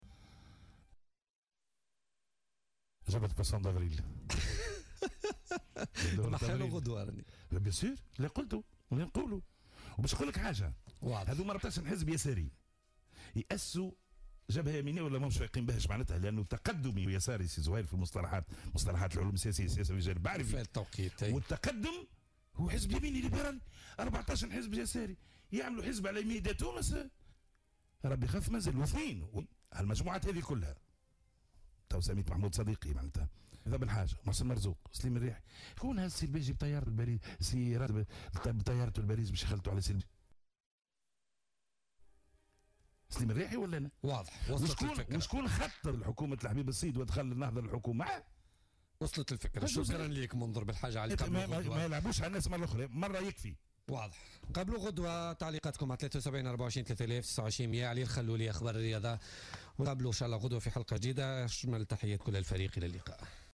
اعتبر النائب منذر بلحاج علي ضيف بولتيكا اليوم الإثنين 10 أفريل 2017 أن جبهة الإنقاذ...